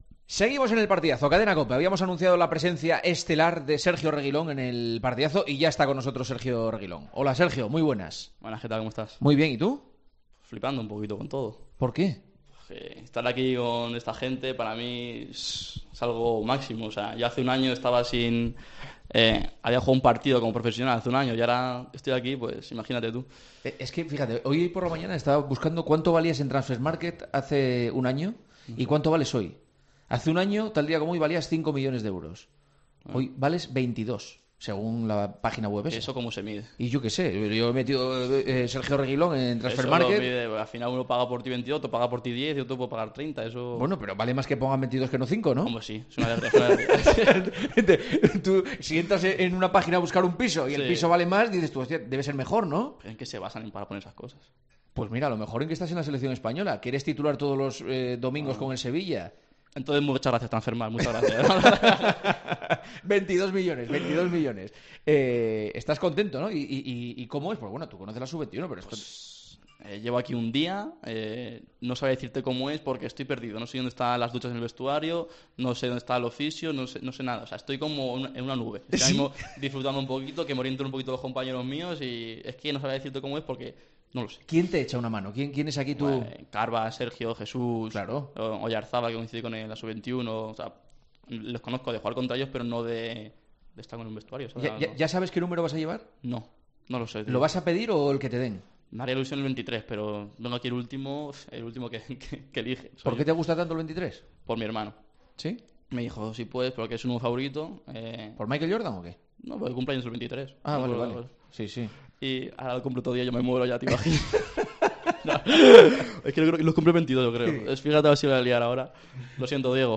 AUDIO: Hablamos con el lateral del Sevilla y ex del Real Madrid en su primera convocatoria con la selección española.